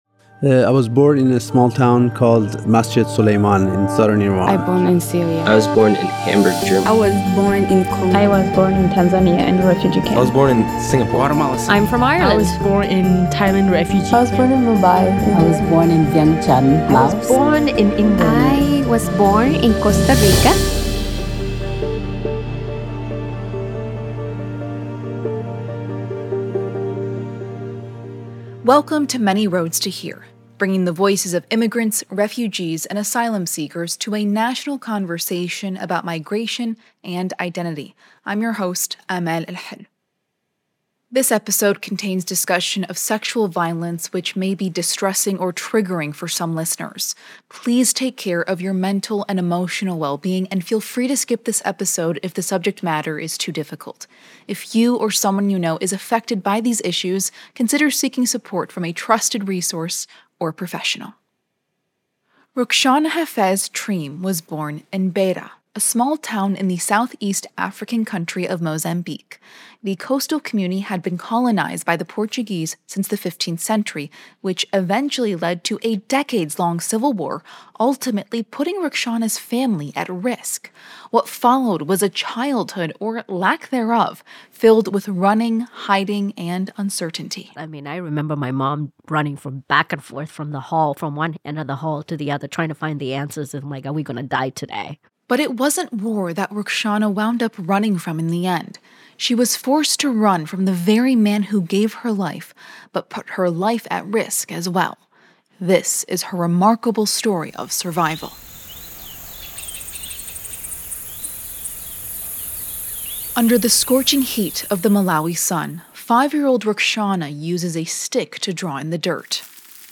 Thank you to St. Andrew Lutheran Church in Beaverton, Oregon, for letting us use their space to record this interview.